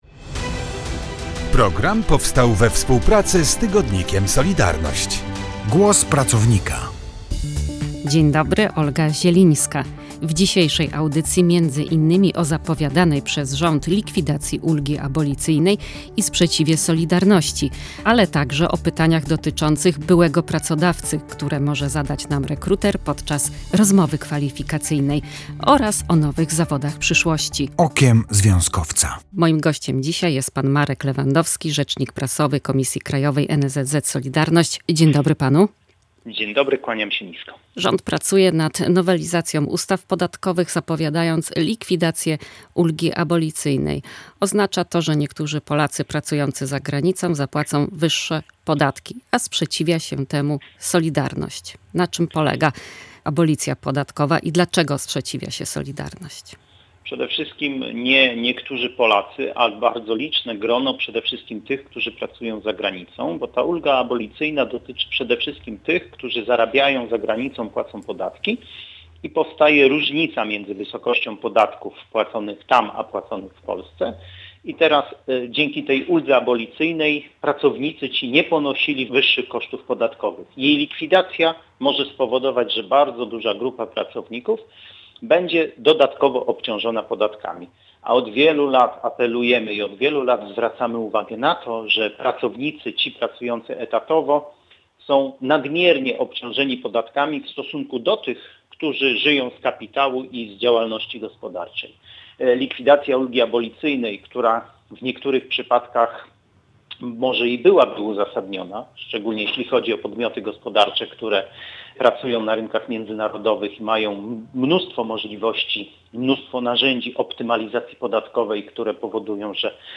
O tym rozmawialiśmy w „Głosie Pracownika”.